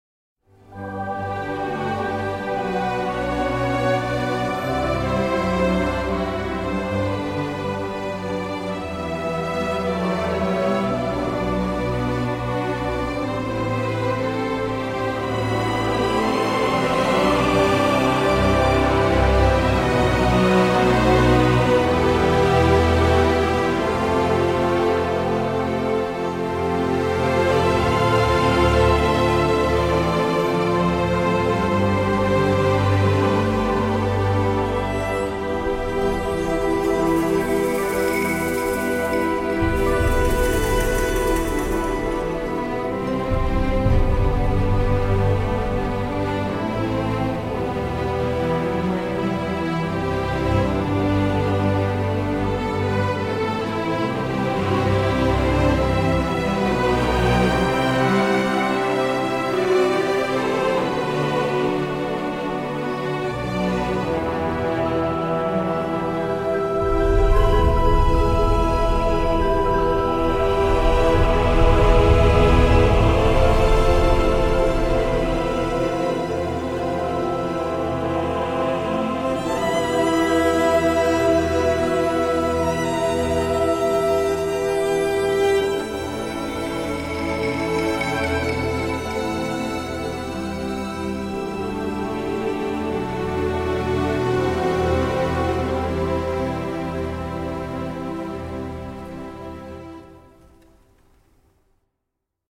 是一个交响弦乐库，包含16把小提琴，12把中提琴，10把大提琴，8把低音提琴，一把独奏竖琴和一个3把小提琴FFF叠加层。
该库提供了一个具有古典倾向的环境音色，并且在标准的管弦乐队座位位置上进行了录制。
- 最多14个动态层
- 使用近距离麦克风和AB麦克风（主/远）进行录制